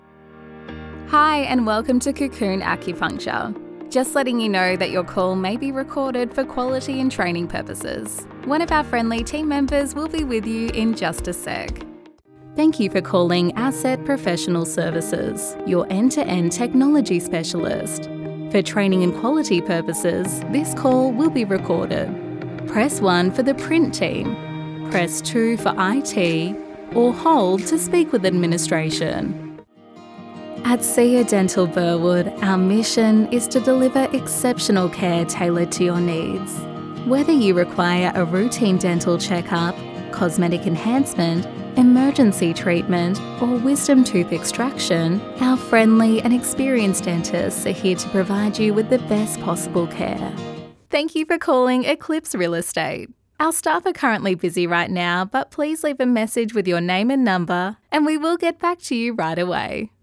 Australian female voiceover artist, with a voice often described as:
Recording from my sound engineer approved home recording studio
Phone Greetings / On Hold
Welcoming, Professional